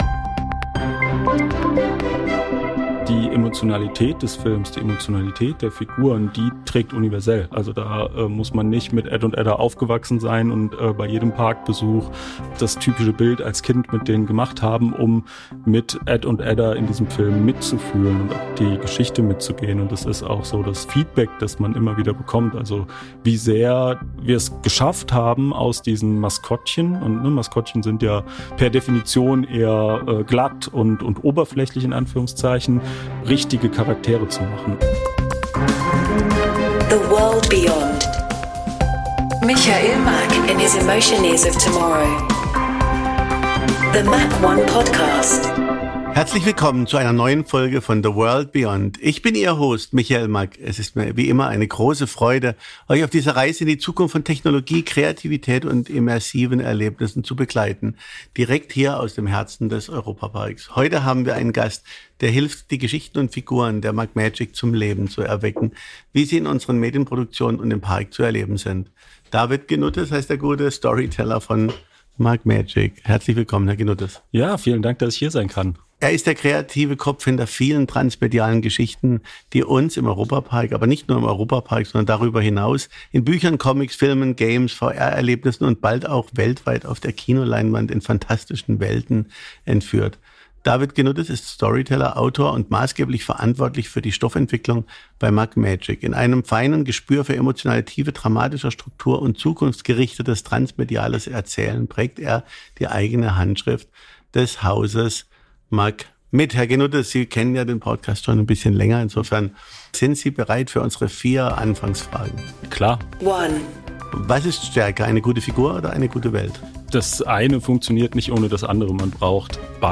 Find out by listening to fascinating conversations in this exclusive time-travel adventure!—MACK One is an international creator and consultant for innovative theme park design, media-based entertainment, and media content. We create and develop ideas for the attractions industry of tomorrow.—This podcast is a MACK One production, recorded at Studio78 in Europa-Park.